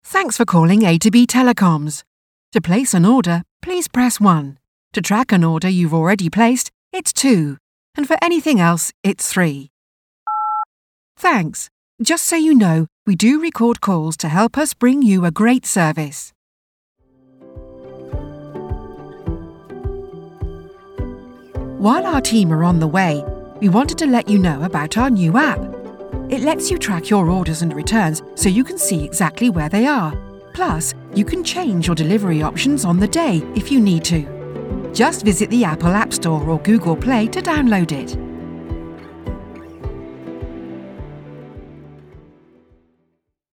Pro Voice Samples
Our voice artists are professionally trained and adaptable, so they can deliver your messaging in a way that suits your brand personality.